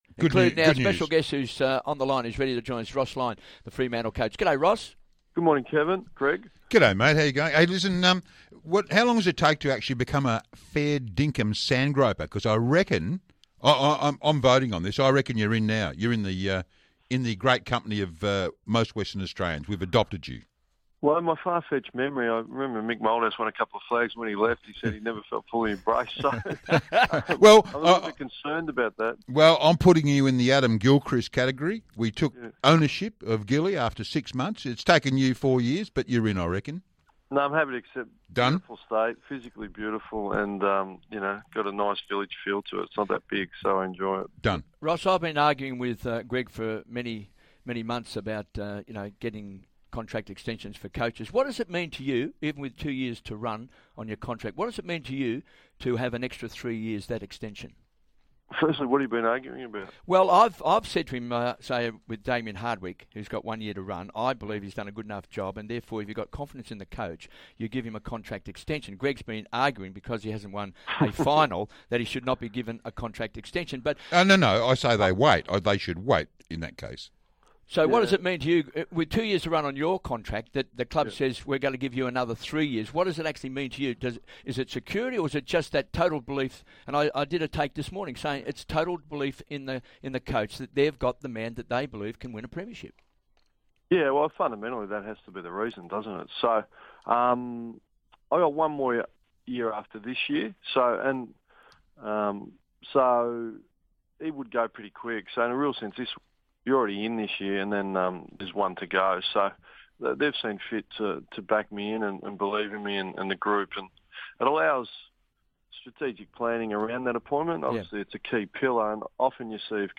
Ross Lyon speaks on 'Hungry for Sport' on SEN